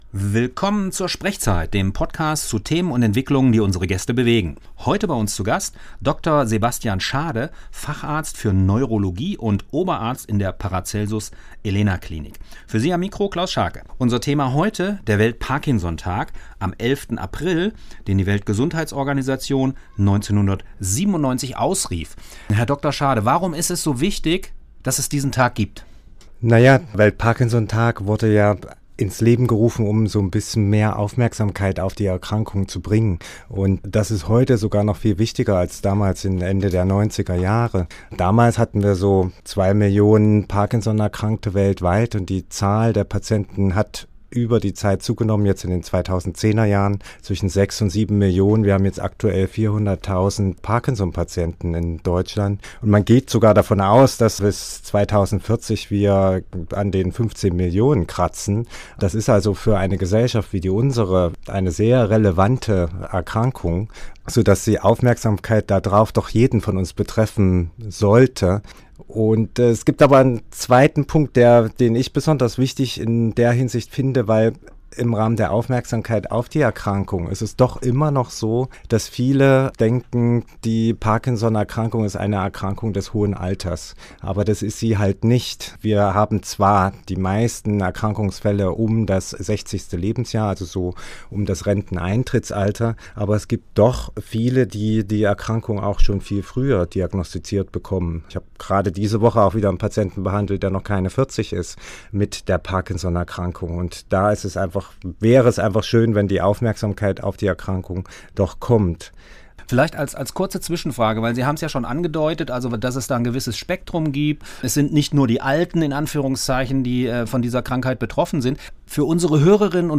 Er bringt Interessierte ebenfalls auf den aktuellen Stand der Forschungen und erklärt, wie jeder einzelne mit seinem Lebensstil einen Beitrag leisten kann, gesund zu bleiben. Dieser SprechZeit-Podcast wurde am 5.4.2024 beim Freien Radio Kassel aufgezeichnet.